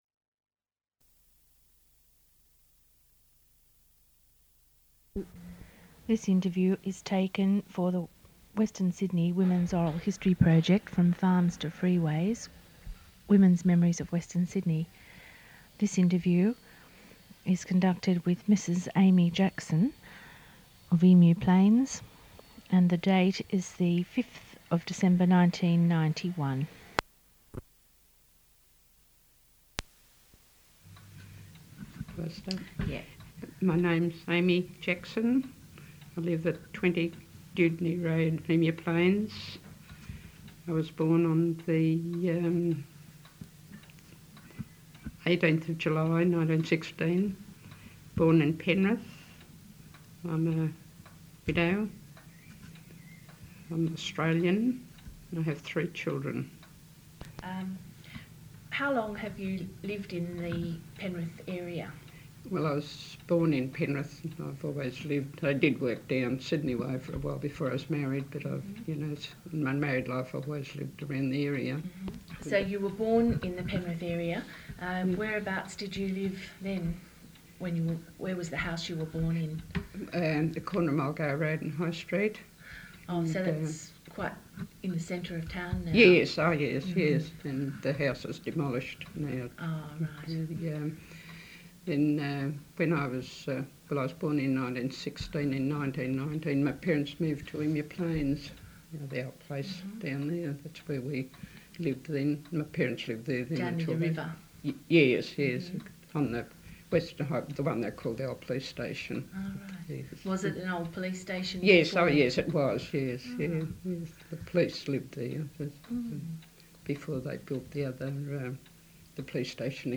audio cassette tape (1)